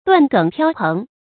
斷梗飄蓬 注音： ㄉㄨㄢˋ ㄍㄥˇ ㄆㄧㄠ ㄆㄥˊ 讀音讀法： 意思解釋： 梗：植物的枝莖；蓬：蓬蒿，遇風常吹折離根，飛轉不已。